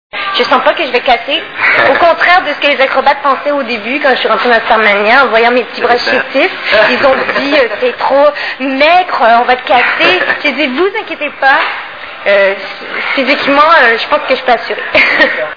STARMANIA...Interview
( Casino de Paris, Hall d'entrée, 06/02/2000 )